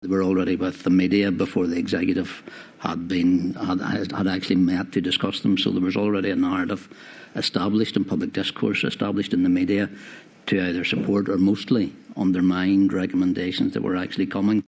Robin Swann has been giving evidence to the UK’s Covid inquiry, which is sitting in Belfast.